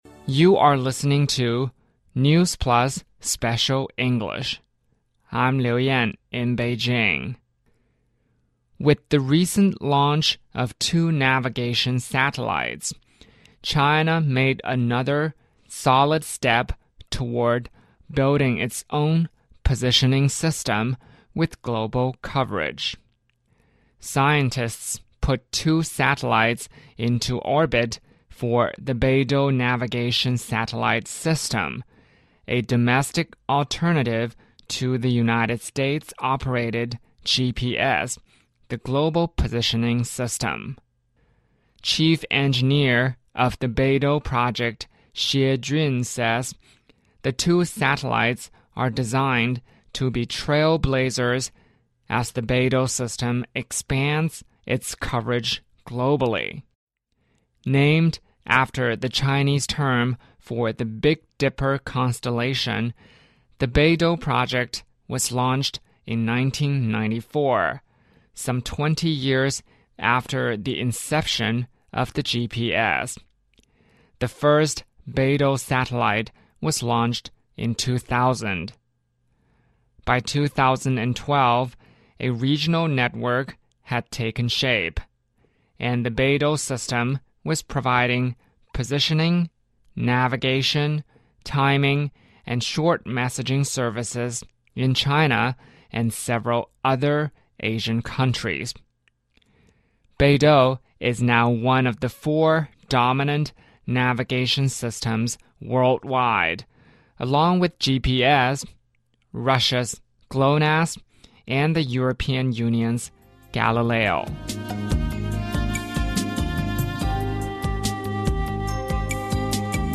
News Plus慢速英语:中国再发射两颗北斗导航卫星 中国或将经历史上最长厄尔尼诺事件